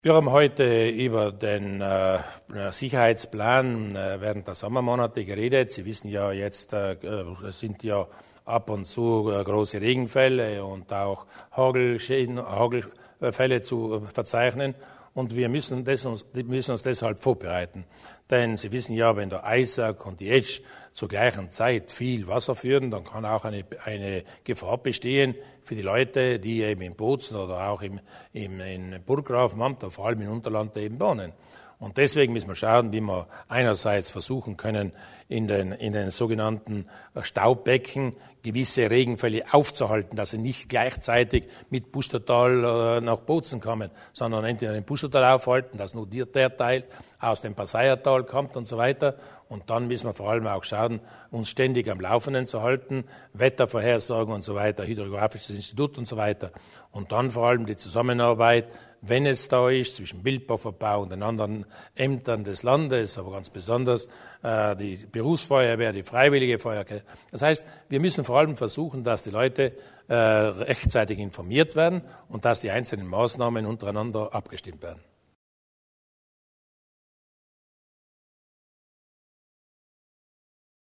Landeshauptmann Durnwalder zum Hochwasserplan